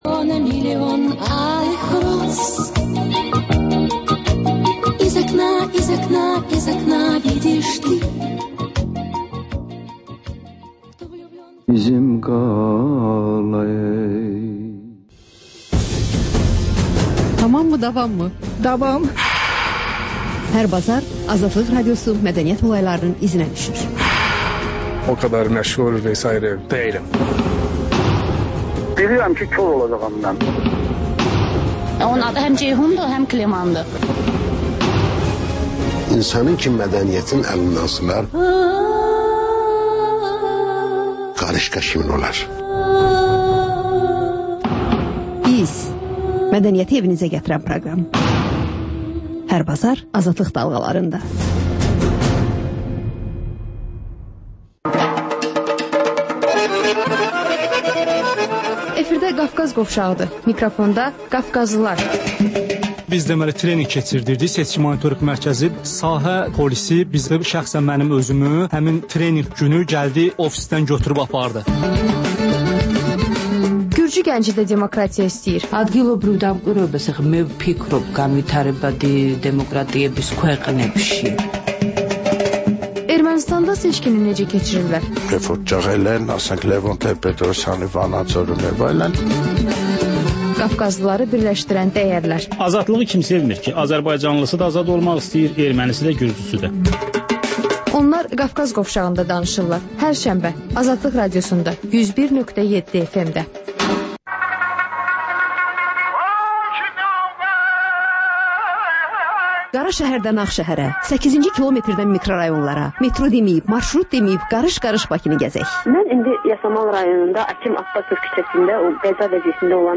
Ölkənin tanınmış simalarıyla söhbət.